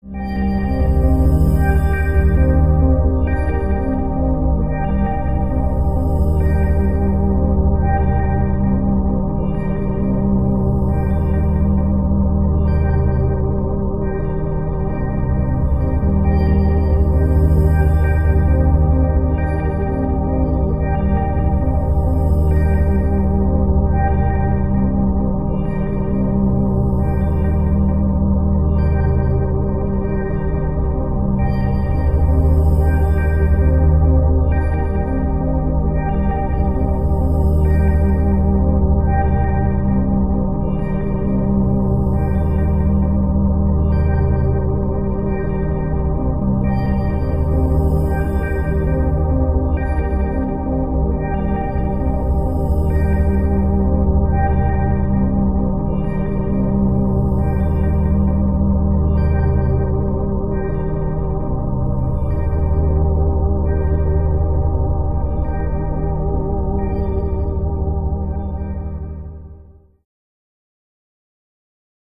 Mystical Rising Tonal Textures Mystical, Rising, Sci-fi